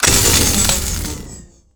overheat.wav